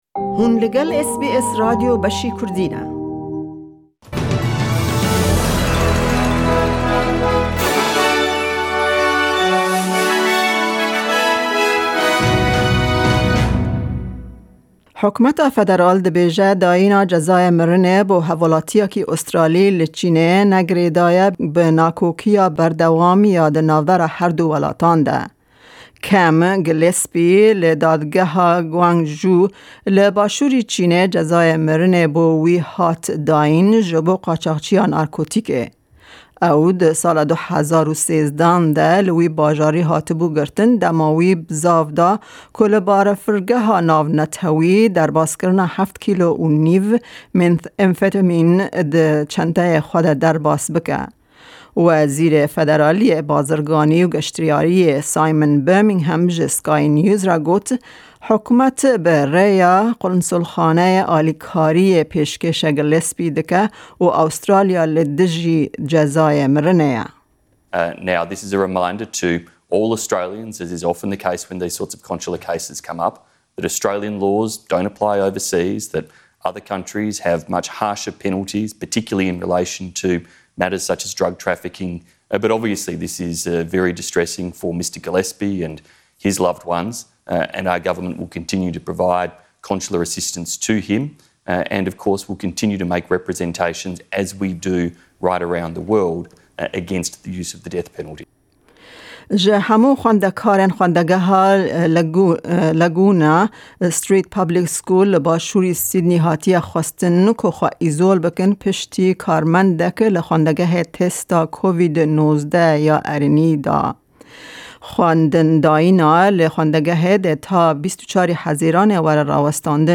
Nûçeyên roja Yekşemê